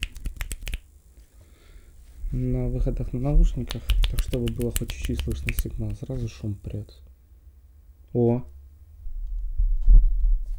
Сталкнулся с такой проблемой: слышимый шум при использовании микрофонов. Причем шум то есть, то пропадает, единовременно на 2-х микрофонах, что привело к мысли что это помехи сети, но замена карты, преампа, и 2-х разных сетей не привело к успеху. Все равно периодически возникает неприятный фон.